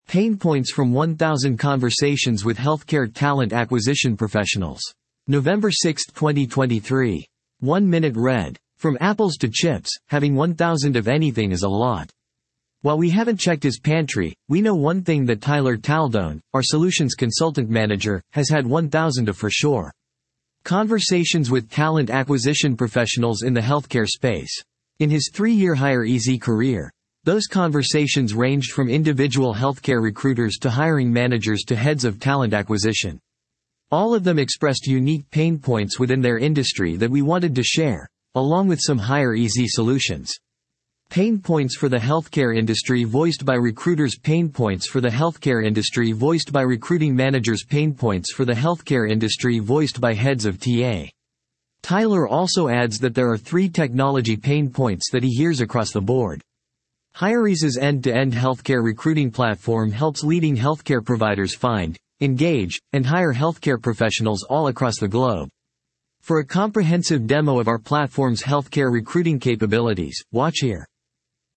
You can use this audio player to convert website page content into human-like speech. 11:11 00:00 / 14:00 1.0X 2.0X 1.75X 1.5X 1.25X 1.0X 0.75X 0.5X From apples to chips, having one thousand of anything is a lot.